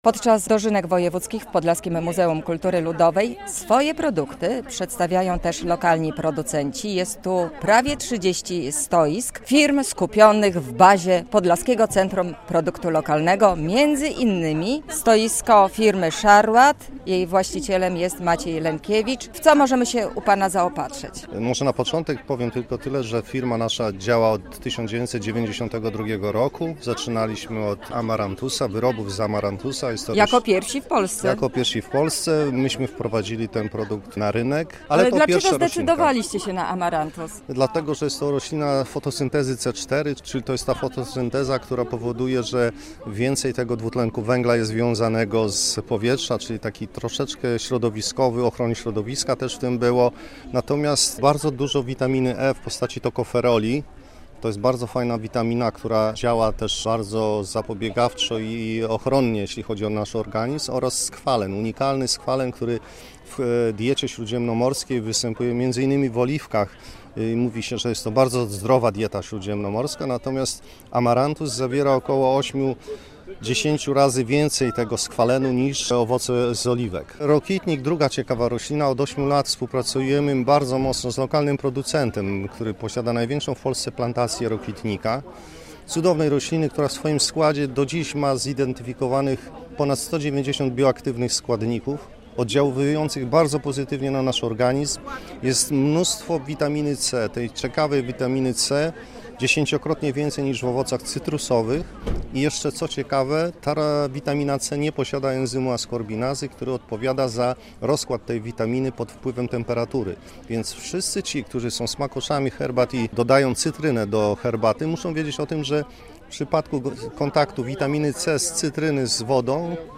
W Podlaskim Muzeum Kultury Ludowej mieszkańcy regionu świętują Dożynki Wojewódzkie - relacja